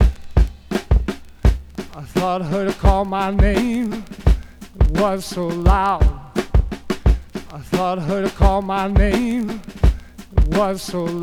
• 92 Bpm Modern Drum Loop Sample A# Key.wav
Free drum loop - kick tuned to the A# note. Loudest frequency: 537Hz
92-bpm-modern-drum-loop-sample-a-sharp-key-ZYG.wav